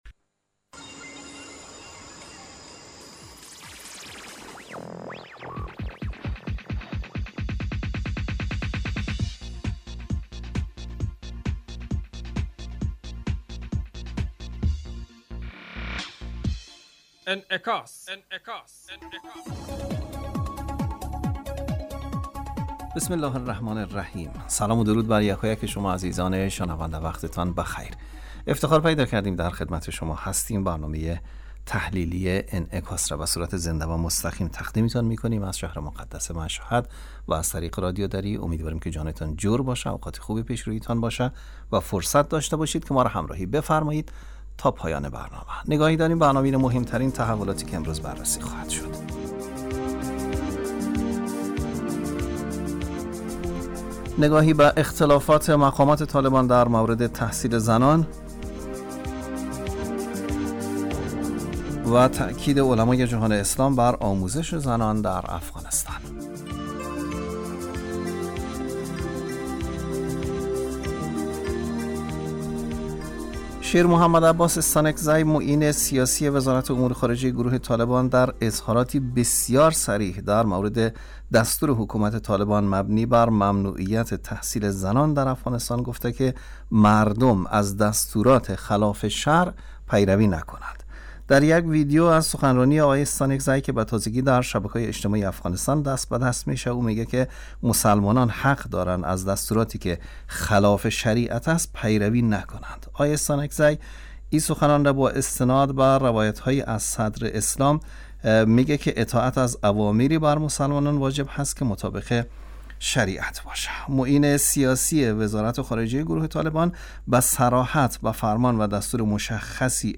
برنامه انعکاس به مدت 35 دقیقه هر روز در ساعت 06:50 بعد ظهر (به وقت افغانستان) بصورت زنده پخش می شود.